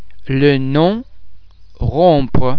The French [on] nasal vowel sound is made up of the [o ] vowel base which is subsequently nasalised by the air being passed through the mouth and the nostrils at the same time.
·om
on_lenom.mp3